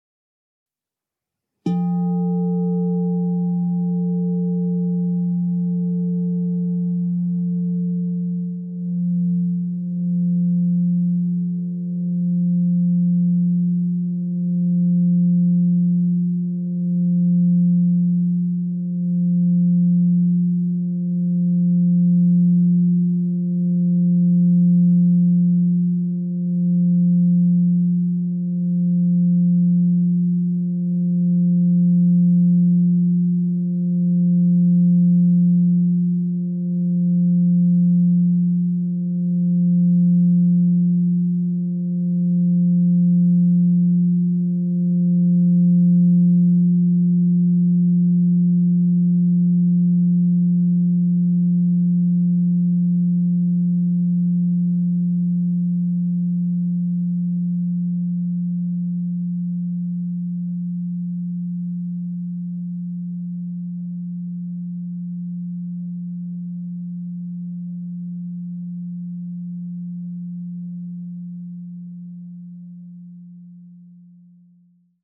Meinl Sonic Energy 12" white-frosted Crystal Singing Bowl F3, 440 Hz, Heart Chakra (CSBM12F3)
Product information "Meinl Sonic Energy 12" white-frosted Crystal Singing Bowl F3, 440 Hz, Heart Chakra (CSBM12F3)" The white-frosted Meinl Sonic Energy Crystal Singing Bowls made of high-purity quartz create a very pleasant aura with their sound and design.